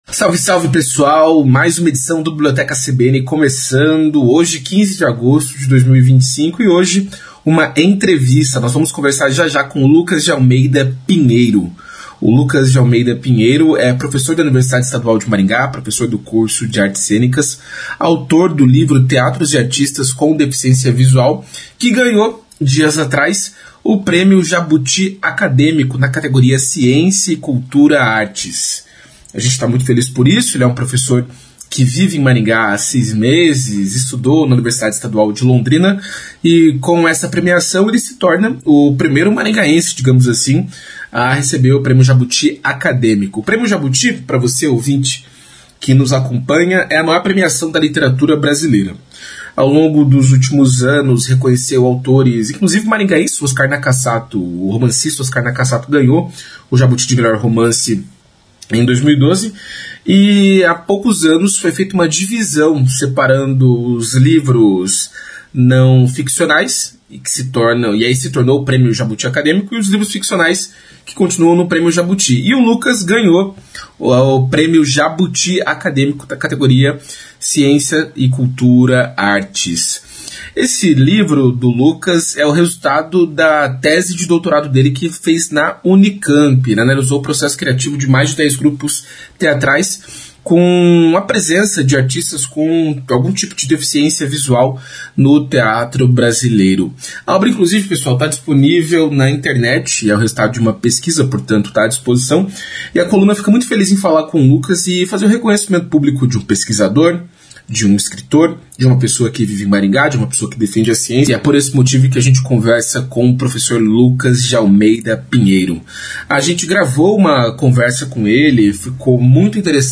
Um prêmio Jabuti entre nós: uma conversa